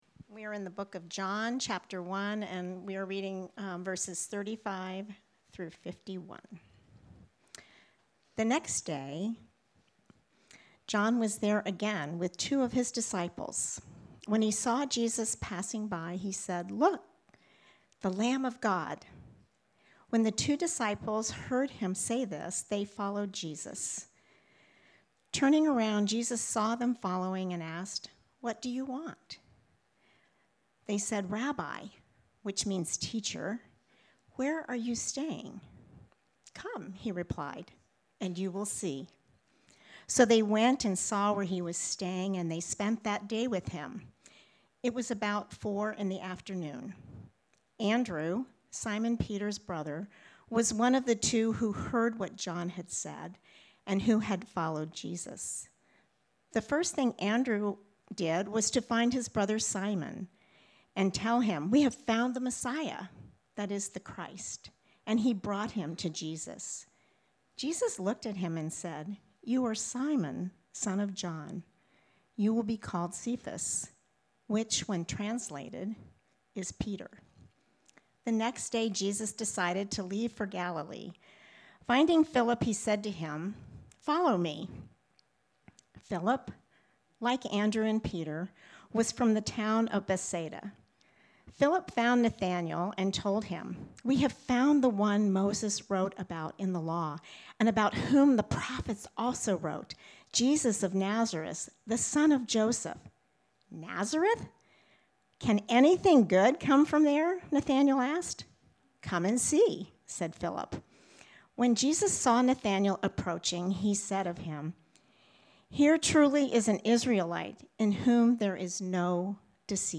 Sermon-1-11-26.mp3